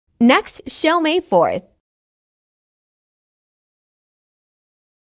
Live Music In LA With Sound Effects Free Download